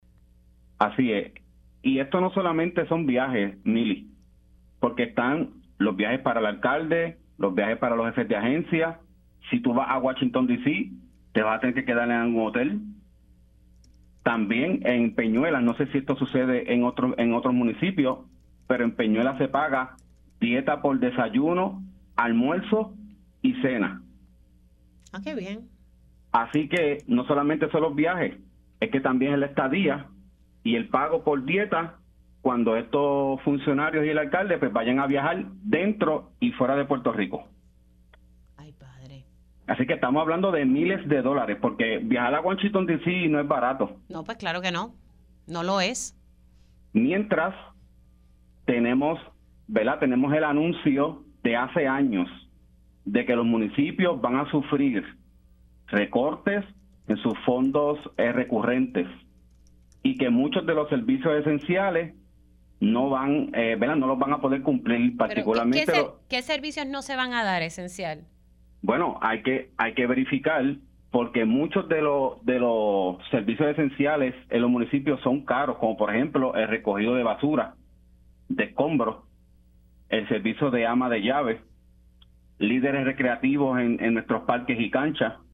El legislador municipal de Peñuelas, Elvin Joel denunció en Pega’os en la Mañana que al aprobarse una ordenanza que creó cabilderos municipales de la estadidad, bajo las reglamentaciones del municipio, se tendrán que pagar las dietas de tres comidas al día y las estadías con fondos públicos.